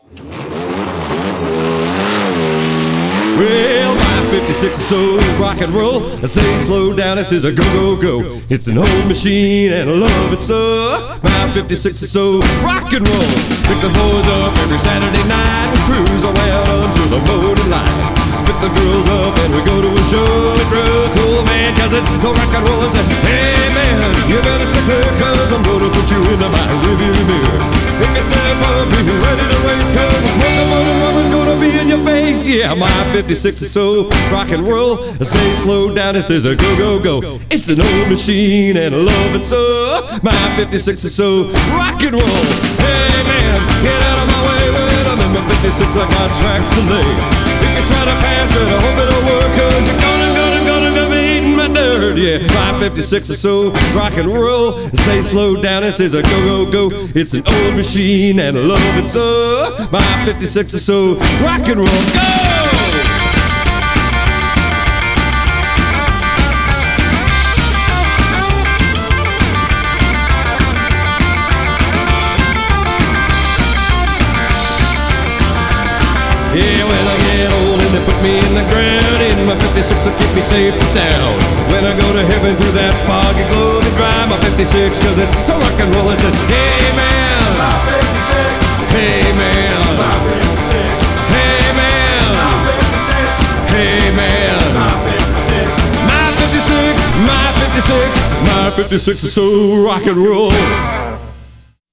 natural low-tenor
speed-billy, car-crazy genre